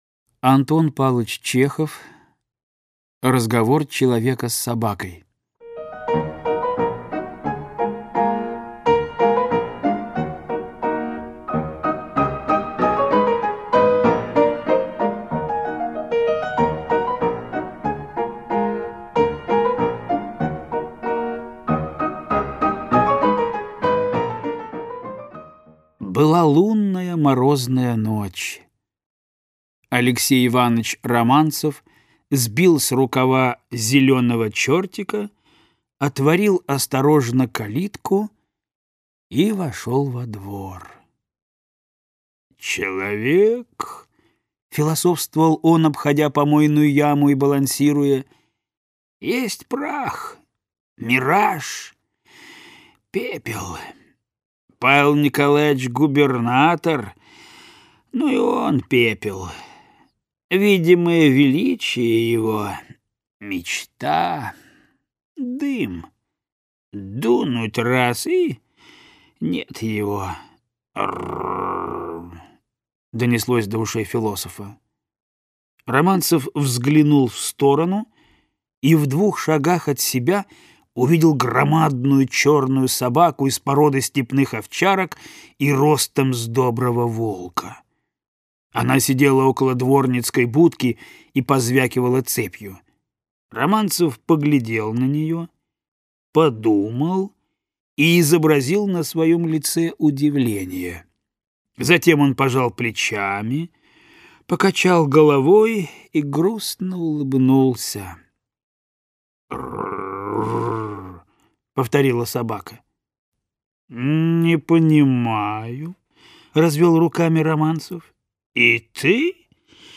Аудиокнига Юмористические рассказы | Библиотека аудиокниг